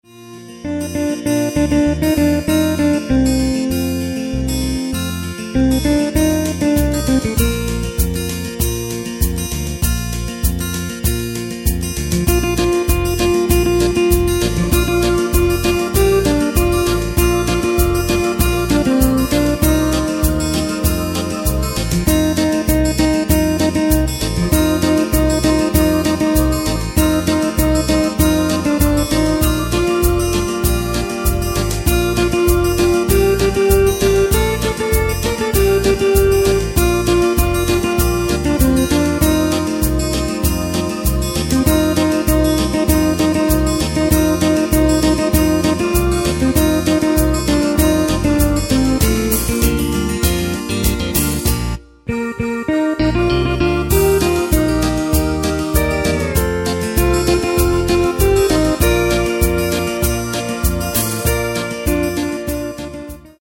Takt:          4/4
Tempo:         98.00
Tonart:            Bb
Country-Hit aus dem Jahr 1971!